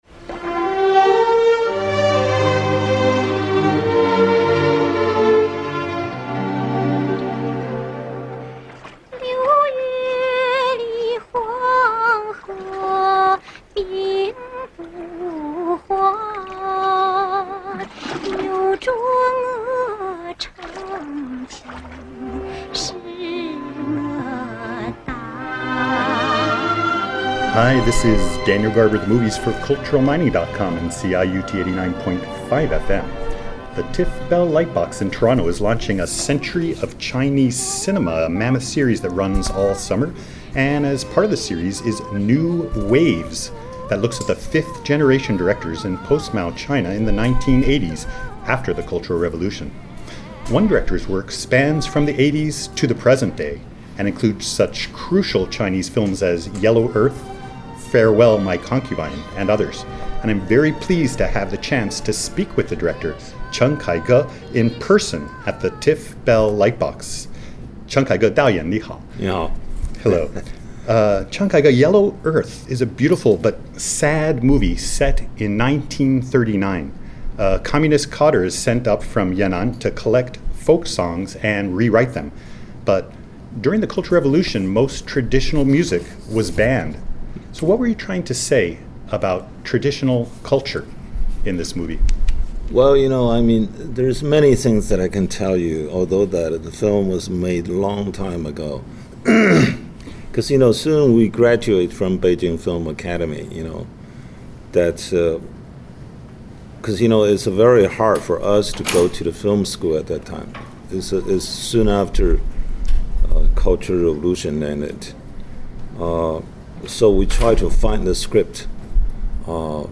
One director’s work stands out, spanning the eighties to the present day and including such crucial Chinese films as Yellow Earth and Farewell My Concubine. In this interview, director CHEN KAIGE tells about making films in the 1980s, the 1990s and today, and talks about traditional culture, Chinese politics, whether Chinese films should “serve the people”, social networking, and more.